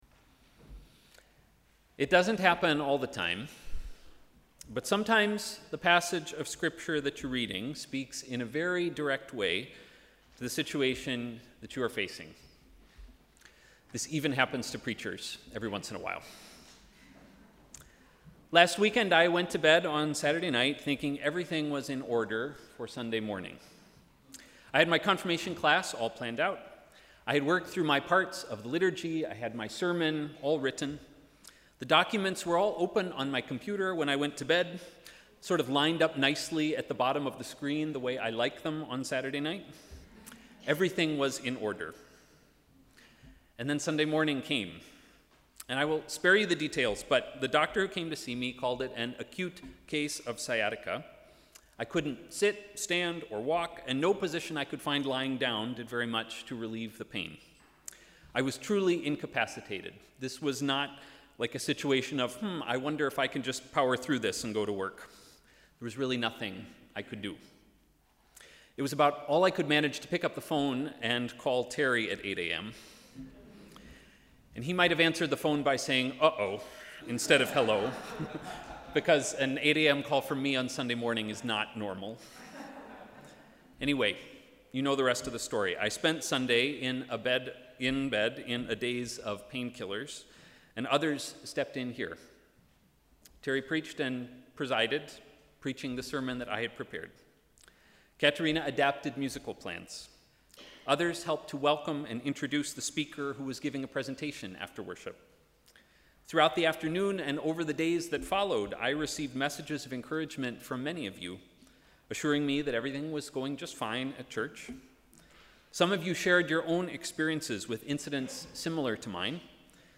Sermon: ‘Simply Christian life’